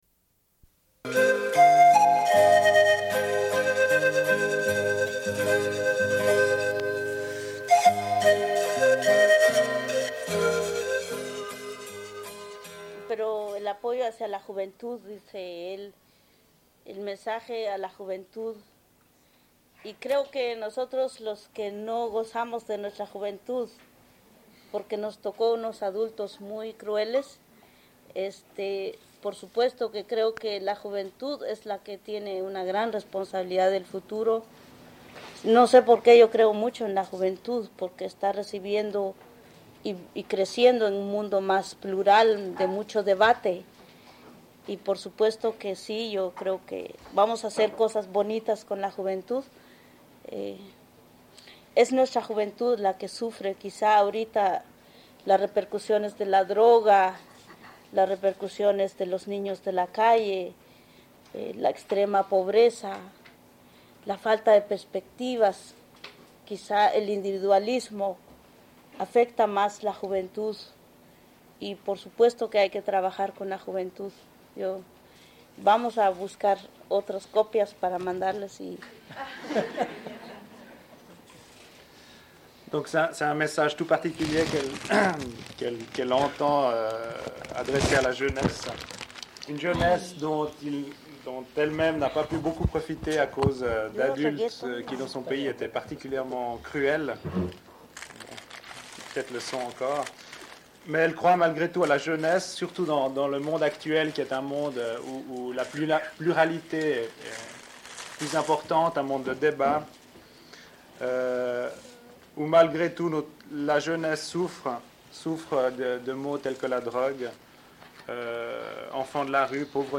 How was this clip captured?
Une cassette audio, face A31:07